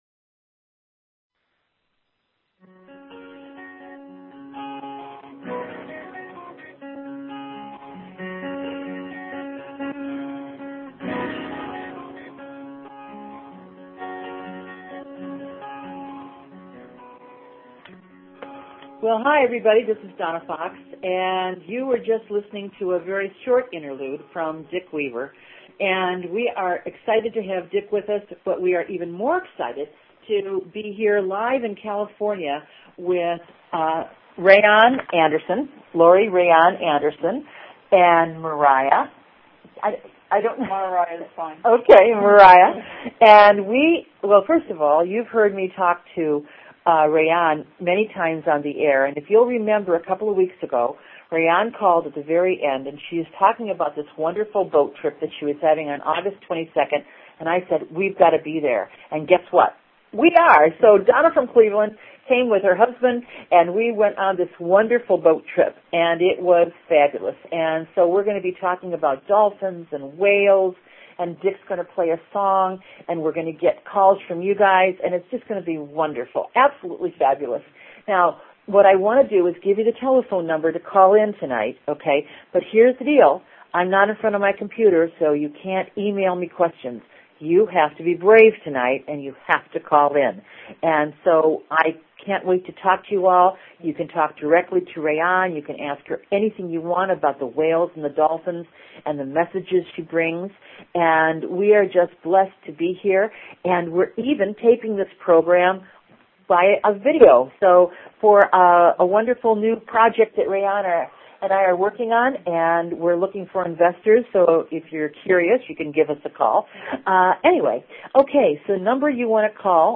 Talk Show Episode, Audio Podcast, Akashic_Wisdom and Courtesy of BBS Radio on , show guests , about , categorized as